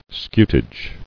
[scu·tage]